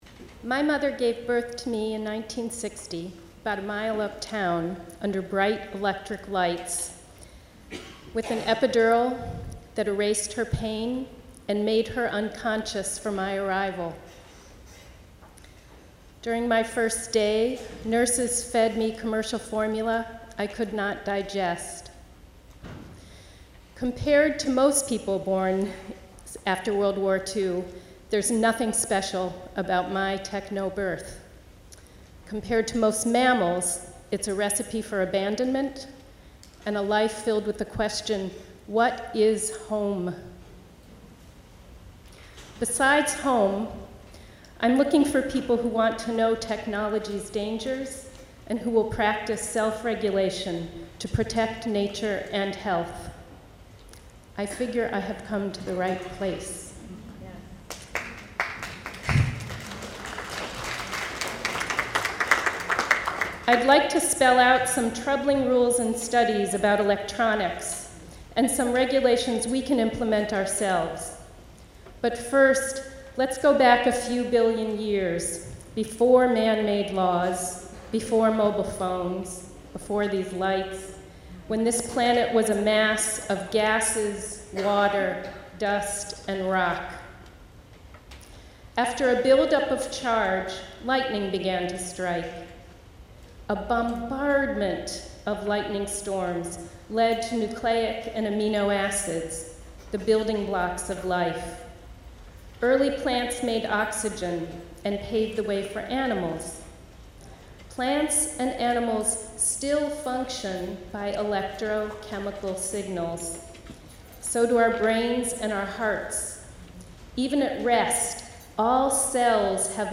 IFG Teach-In